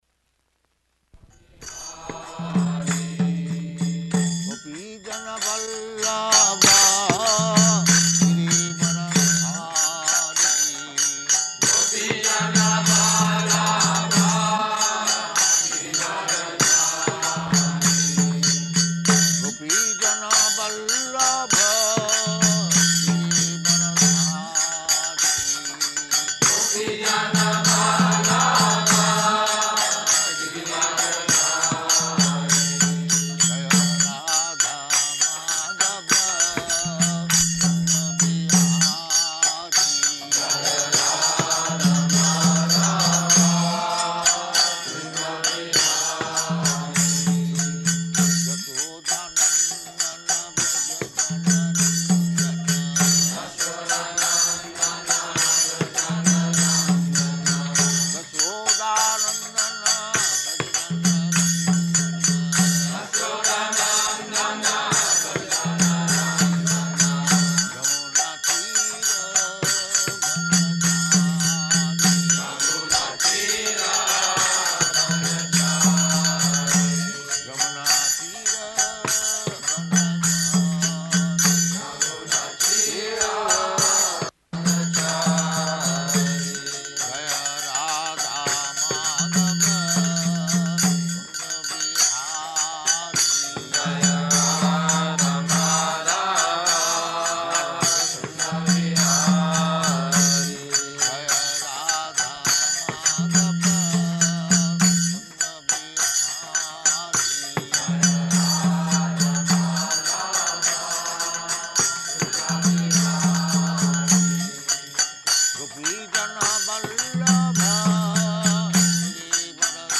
Location: Paris
740609SB.PAR.mp3 Prabhupāda: [leads singing of Jaya Rādhā-Mādhava ]
[devotees repeat]